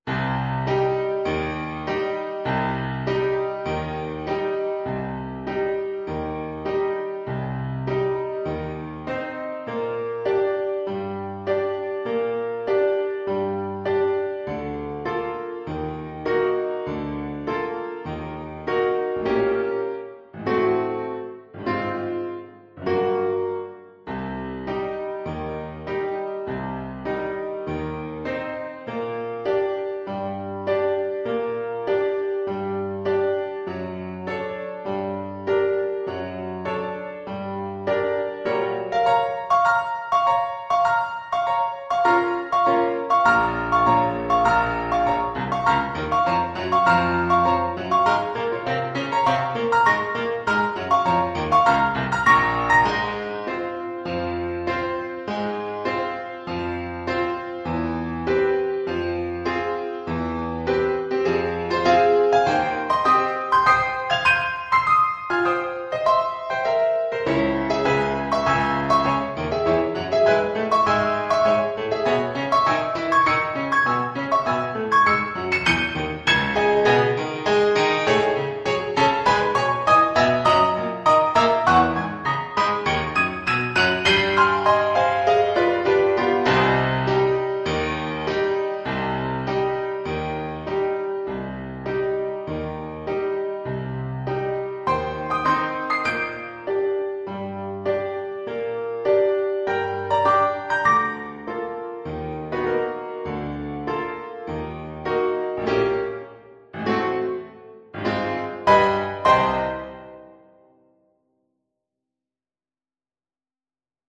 4/4 (View more 4/4 Music)
Classical (View more Classical Saxophone Music)